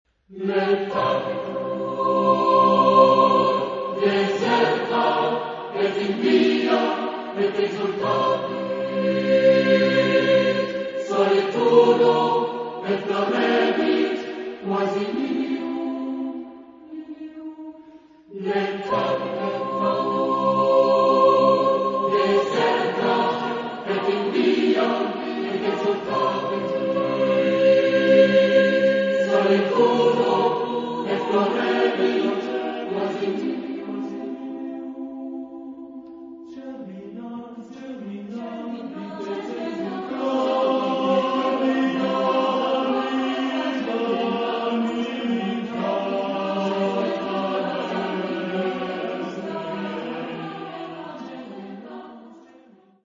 Genre-Style-Forme : Sacré ; Durchkomponiert ; Double chœur
Caractère de la pièce : enthousiaste
Type de choeur : SATB + SATB  (8 voix mixtes )
Solistes : Ténor (1)  (1 soliste(s))
Tonalité : polytonal